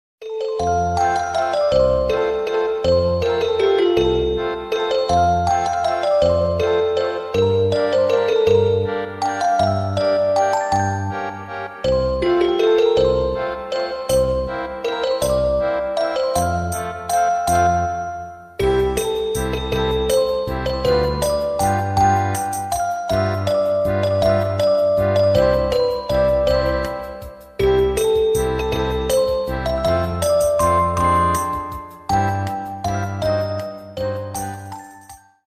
• Качество: 192, Stereo
инструментальные
колокольчики